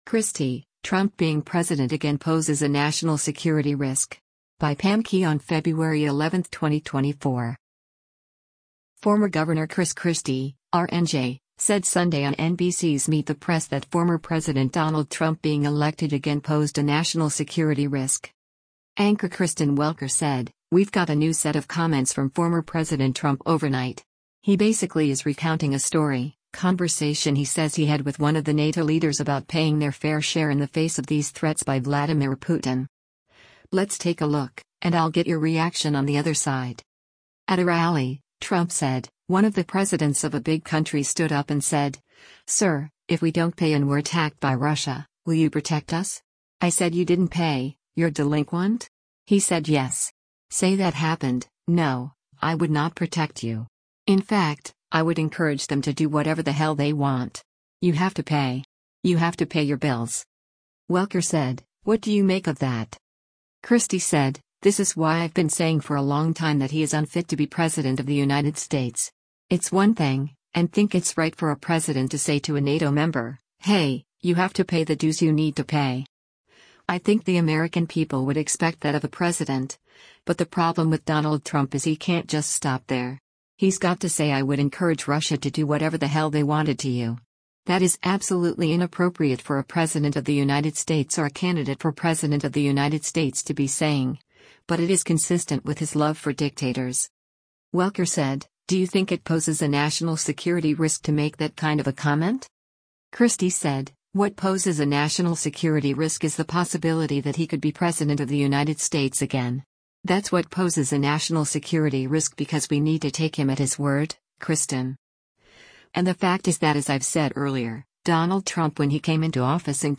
Former Gov. Chris Christie (R-NJ) said Sunday on NBC’s “Meet the Press” that former President Donald Trump being elected again posed “a national security risk.”
At a rally, Trump said, “One of the presidents of a big country stood up and said, sir, if we don’t pay and we’re attacked by Russia, will you protect us?